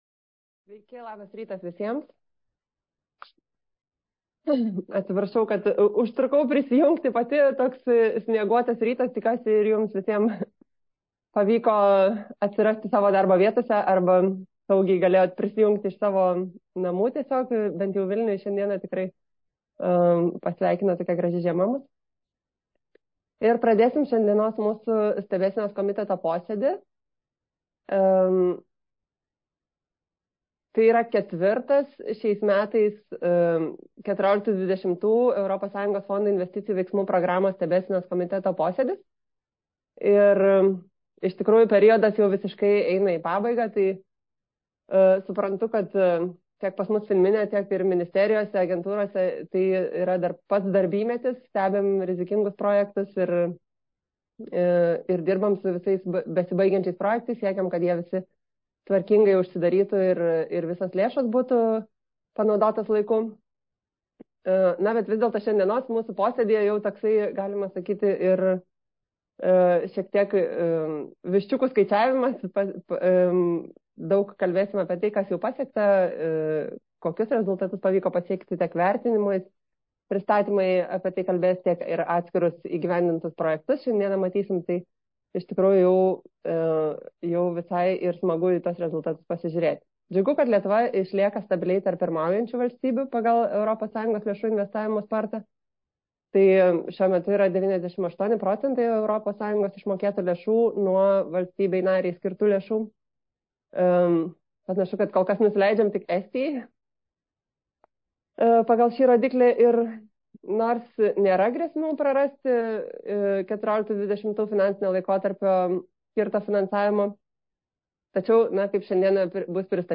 2014–2020 m. ES fondų investicijų veiksmų programos stebėsenos komiteto posėdis (83)